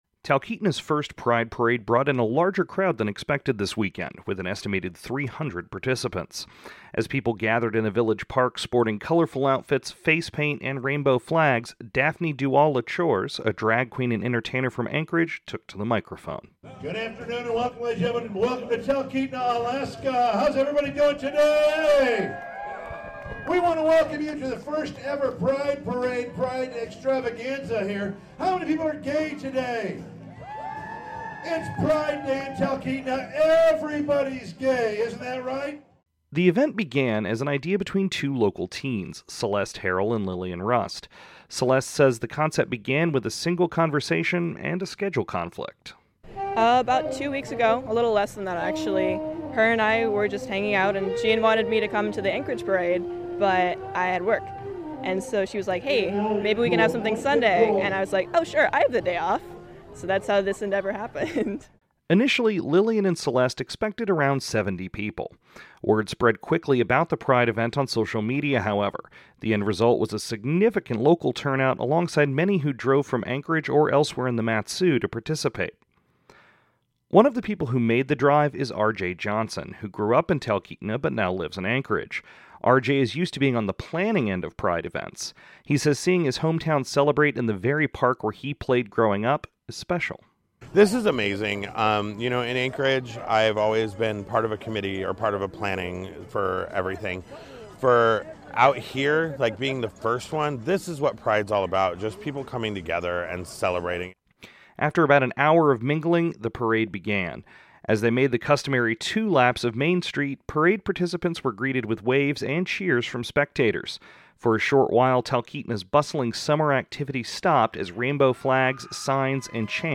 As they made the customary two laps of Main Street, parade participants were greeted with waves and cheers from spectators. For a short while, Talkeetna’s bustling summer activity stopped as rainbow flags, signs, and chants filled the street.